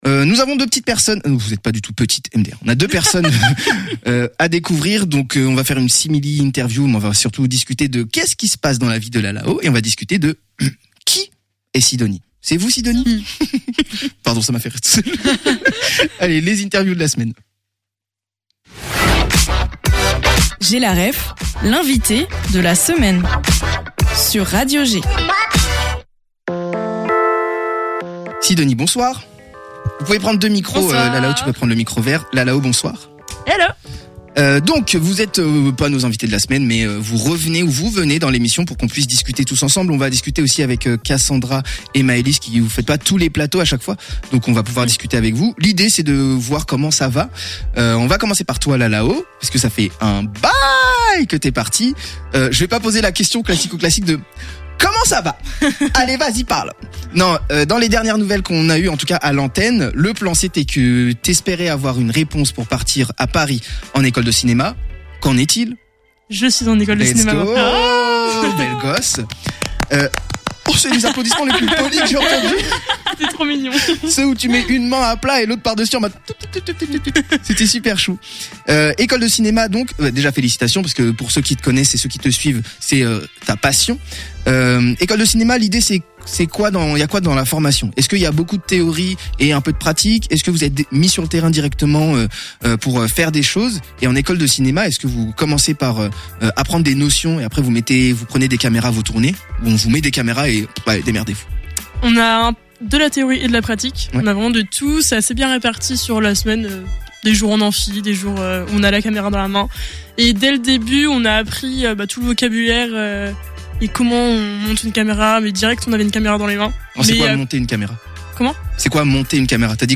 Interview de tout le monde - G!